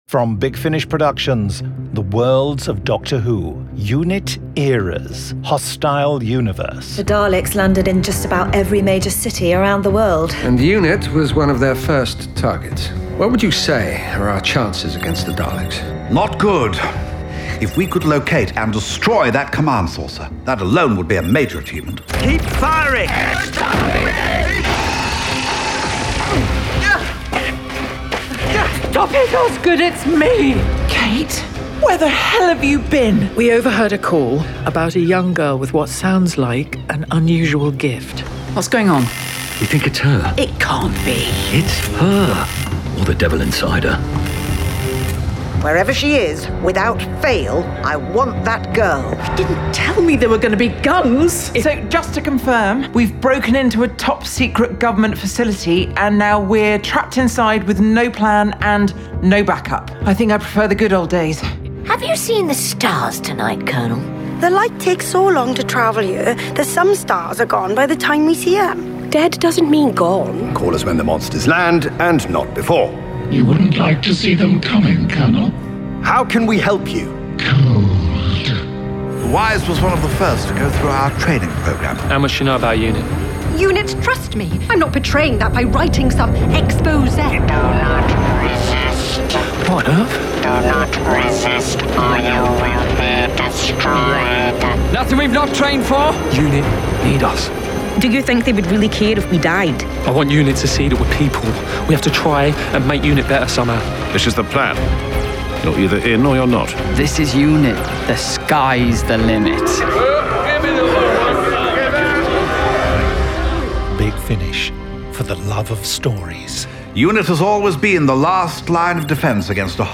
Award-winning, full-cast original audio dramas from the worlds of Doctor Who, Torchwood, Blake's 7, Class, Dark Shadows, Avengers, Omega Factor, Star Cops, Sherlock Holmes, Dorian Gray, Pathfinder Legends, Prisoner, Adam Adamant Lives, Space 1999, Timeslip, Terrahawks, Space Precinct, Thunderbirds, Stingray, Robin Hood, Dark Season, UFO, Stargate, V UK, Time Tunnel, Sky, Zygon Century, Planet Krynoid, Turpin, Young Bond